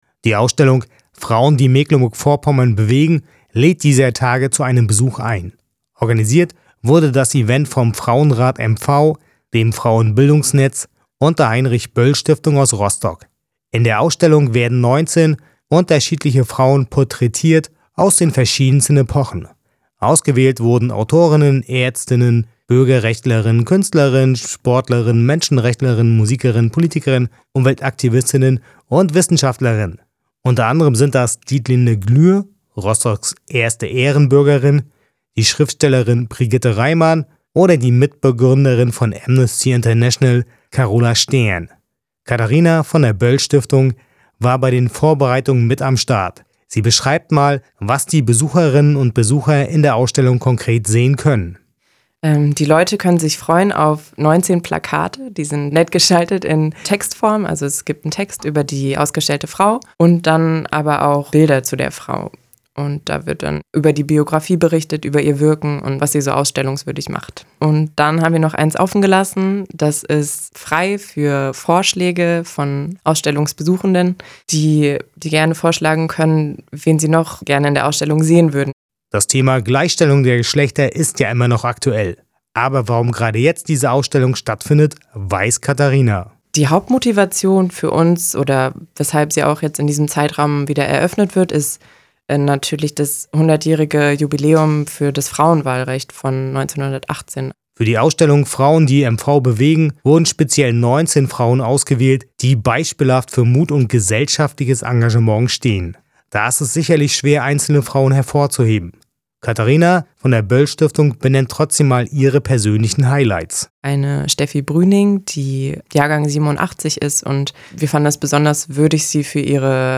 Im Interview: